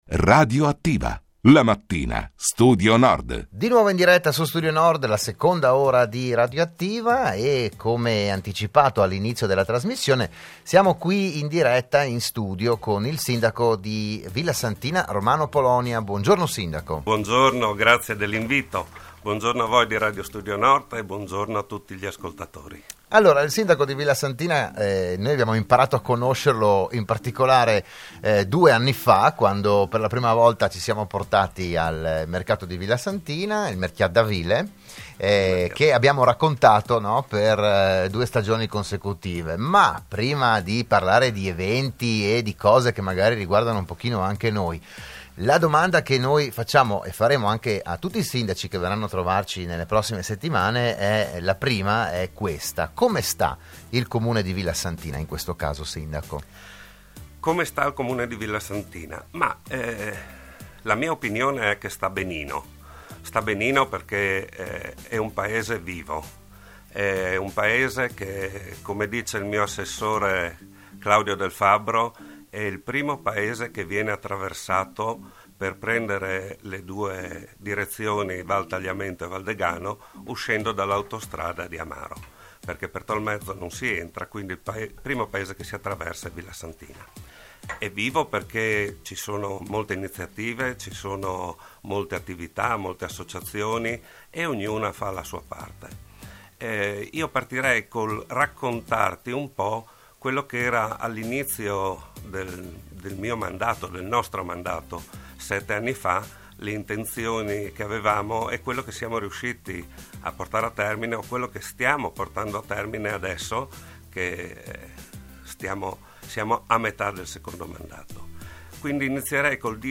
Periodicamente sarà ospite negli studi di RSN un sindaco del territorio per parlare della sua comunità, delle prospettive, degli eventi e quant’altro.